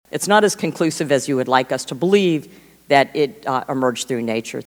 Miller-Meeks made her comments during a House Select Subcommittee on the Coronavirus Pandemic.